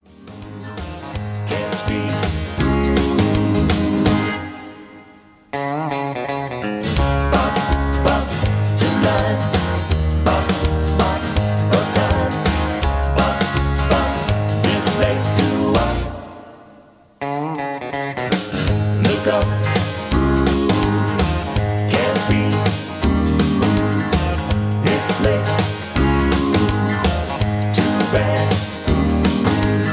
Key-Ab